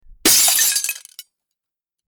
Glass Breaking
Glass_breaking.mp3